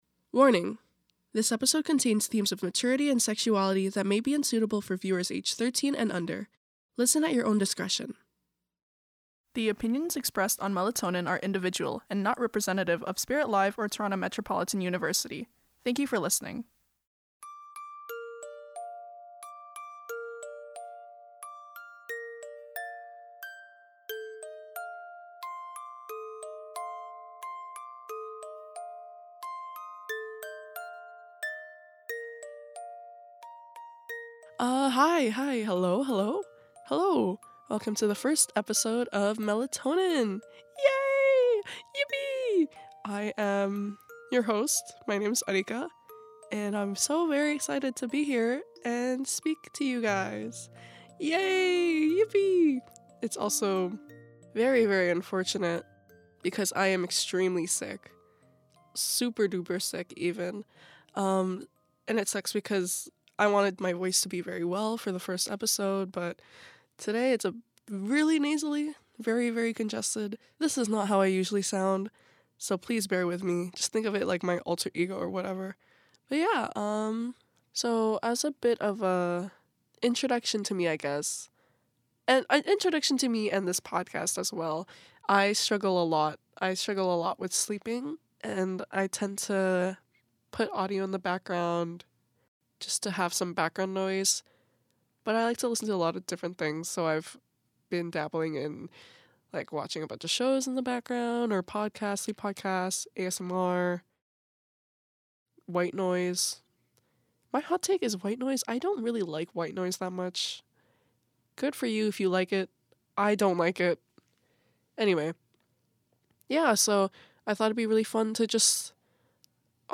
The SICKEST bedtime stories ever - SpiritLive Radio